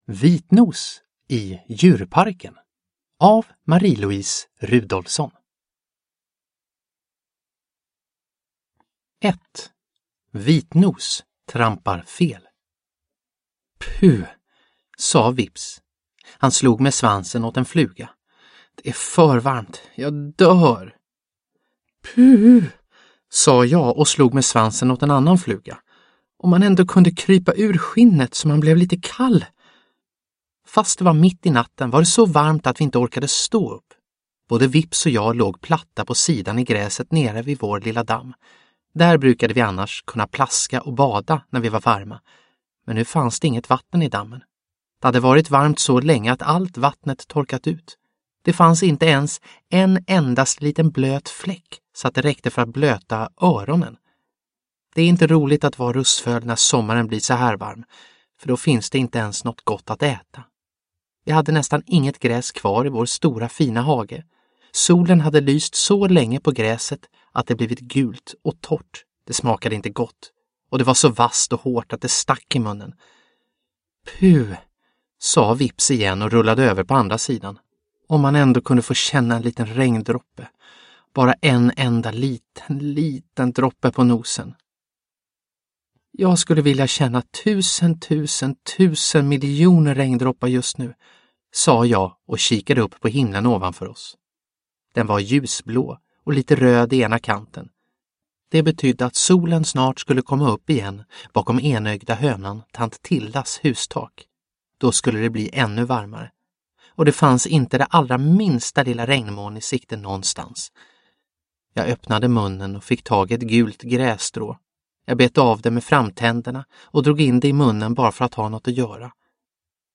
Vitnos i djurparken – Ljudbok – Laddas ner